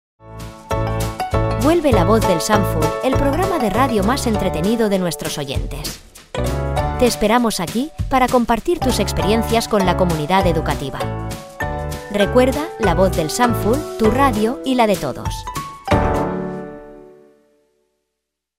AUDIOS PARA PUBLICIDAD
– Anuncio 01 (tu radio y la de todos).
Anuncio-radiosanful-01.mp3